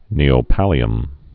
(nēō-pălē-əm)